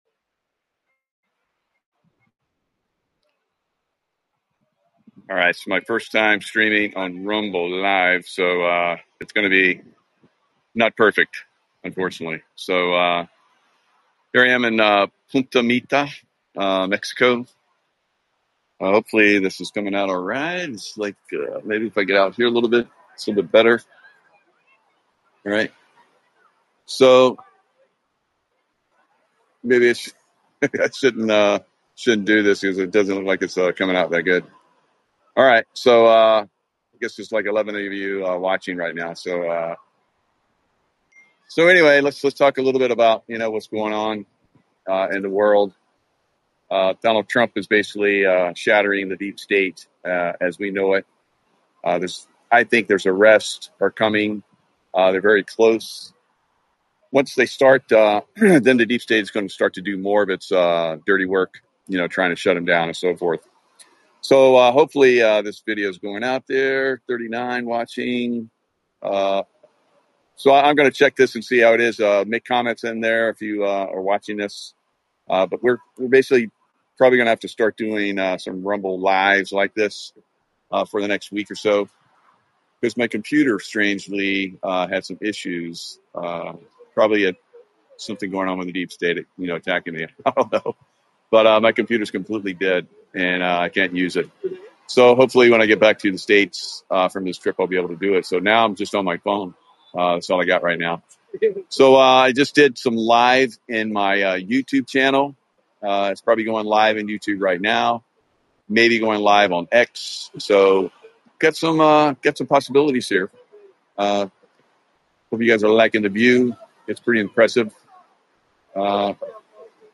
➡ The speaker is trying out live streaming on Rumble for the first time from Punta Mita, Mexico, but is unsure about the video quality. They discuss current world events, specifically mentioning Donald Trump’s impact on the deep state and anticipating future arrests.